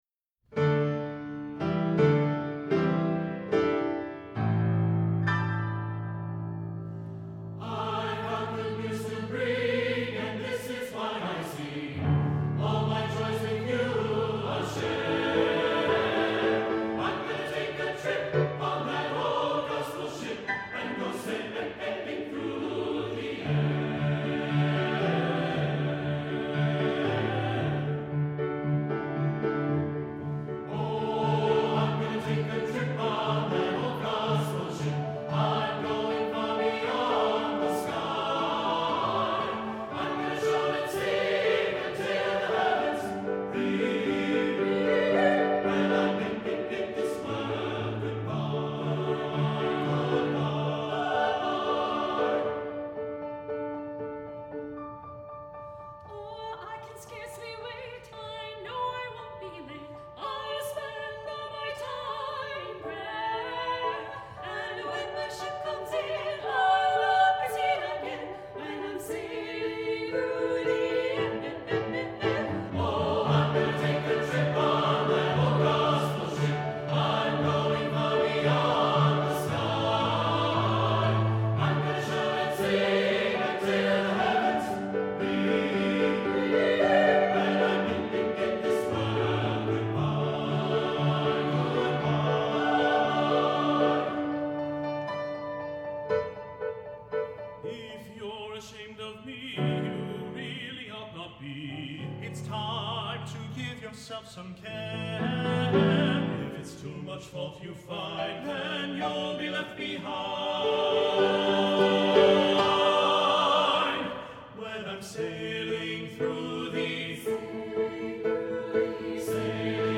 for SSA Chorus, Opt. Children's Chorus, and Piano (2012)
For this is a song of celebration.